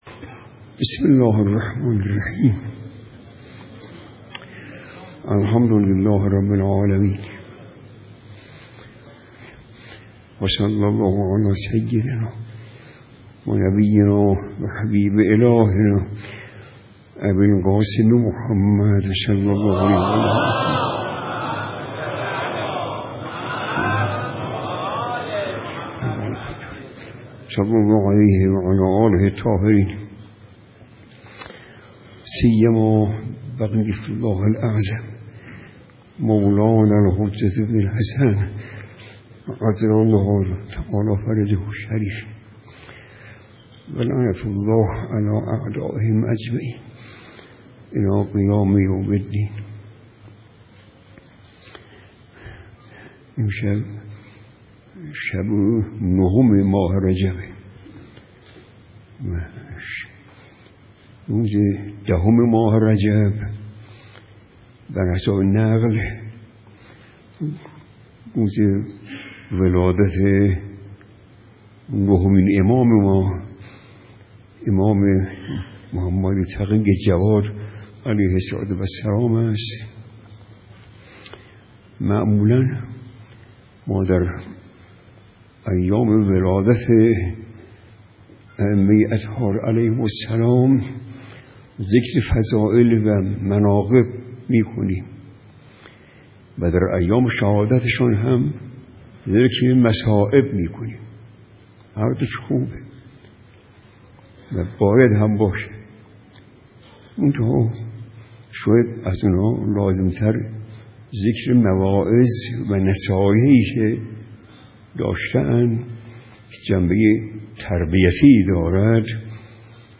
سخنرانی
درشب ولادت امام جواد (ع)